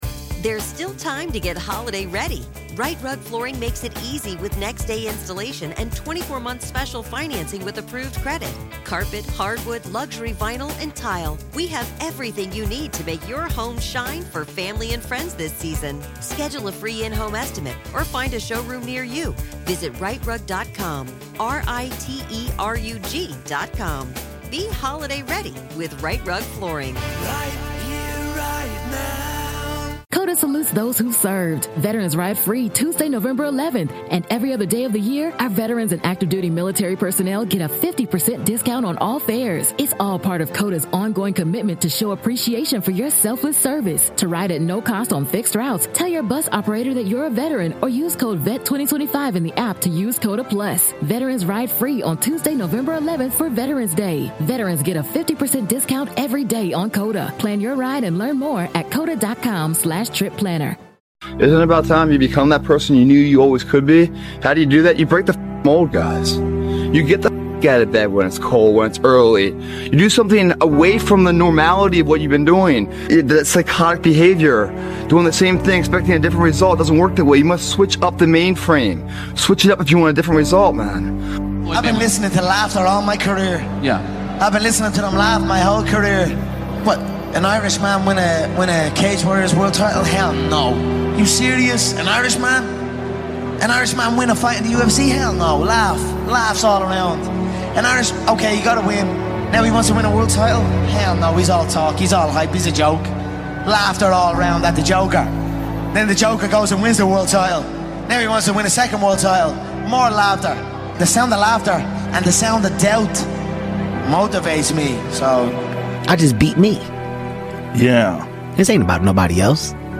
Motivational speeches from Dana White, a true testament to betting against the odds.